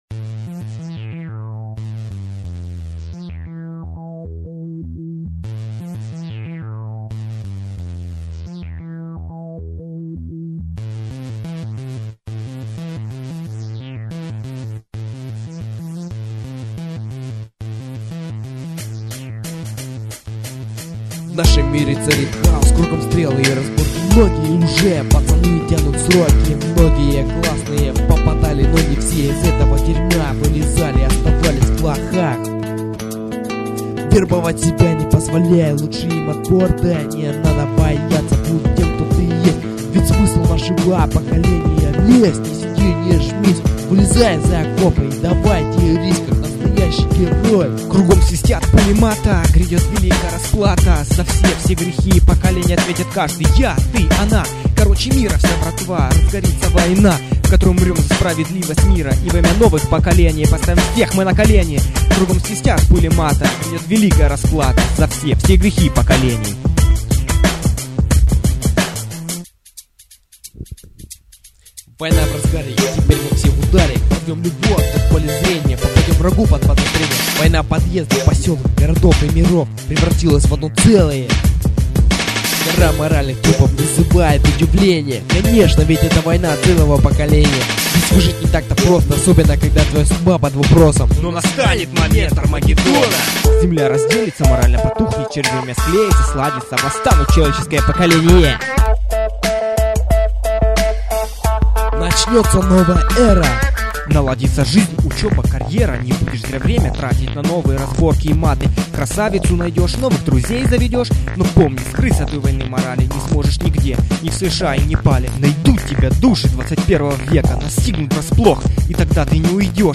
реп группы